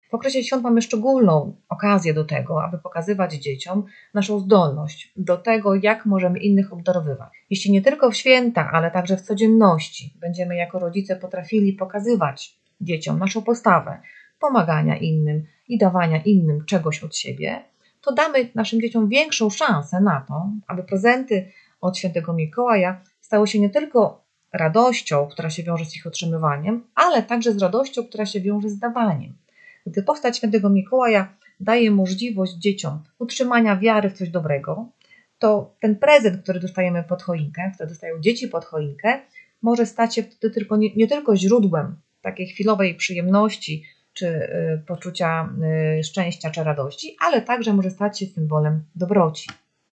Rozmowa z psychologiem